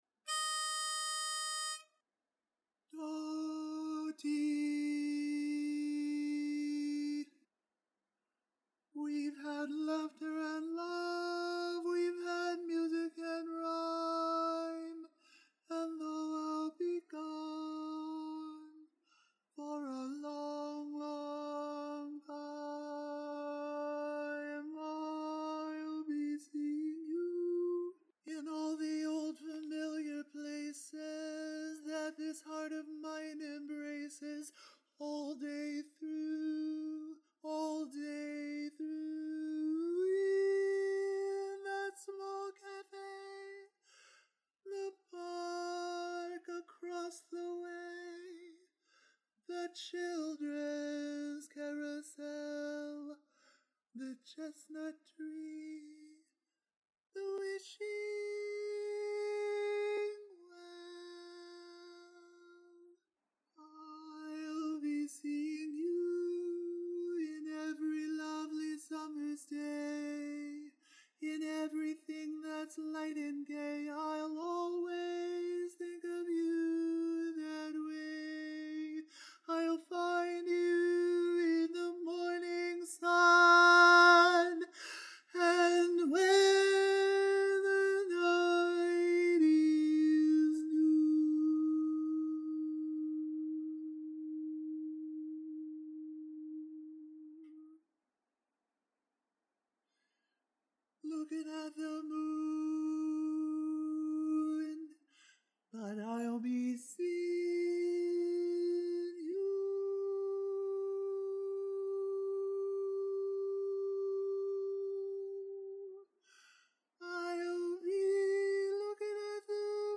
Ballad
Barbershop
E♭ Major
Tenor